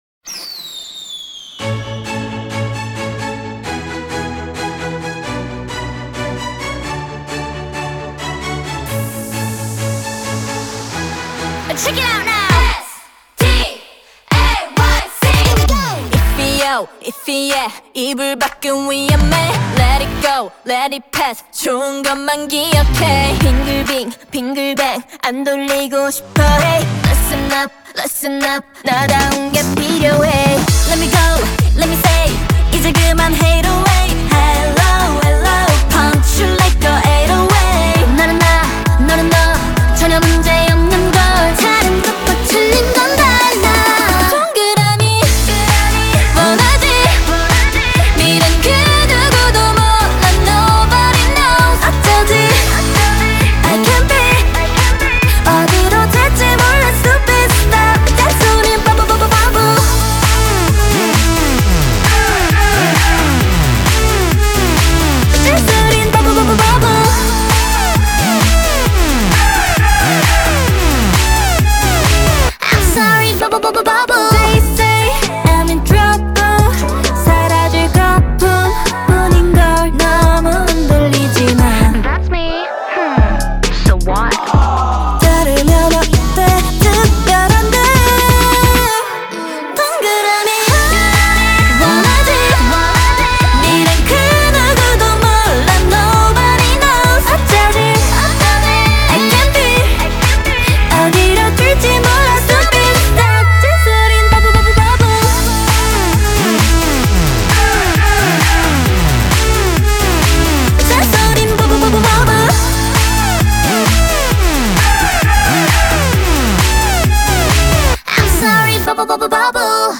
BPM132
Audio QualityPerfect (High Quality)